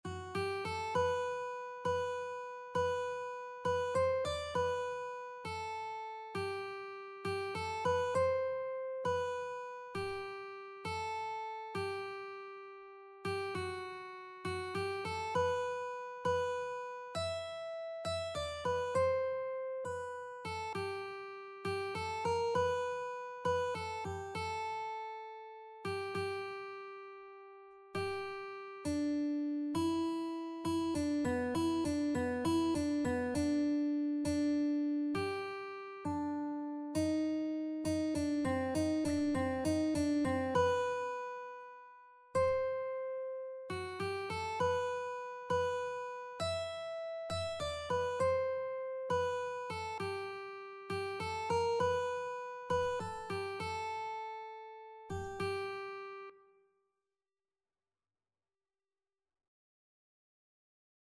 G major (Sounding Pitch) (View more G major Music for Lead Sheets )
6/8 (View more 6/8 Music)
Classical (View more Classical Lead Sheets Music)